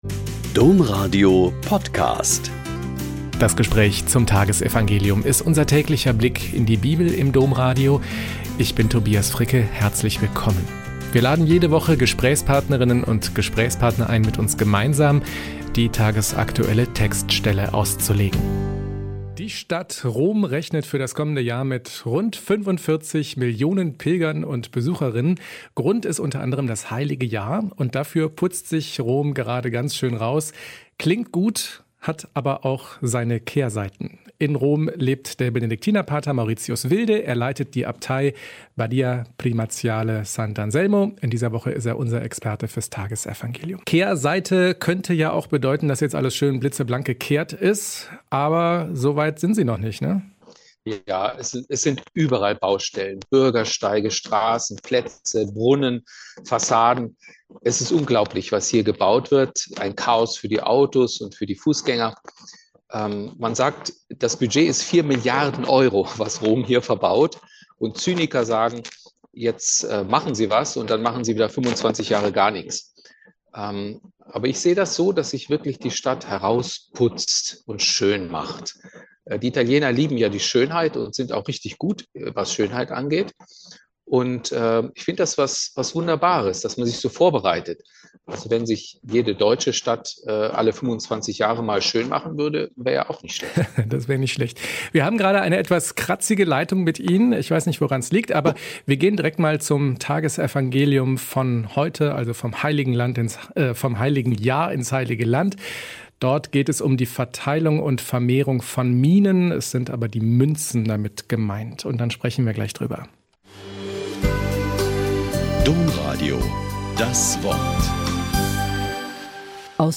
Lk 19,11-28 - Gespräch